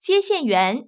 ivr-operator.wav